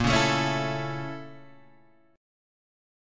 BbmM7b5 chord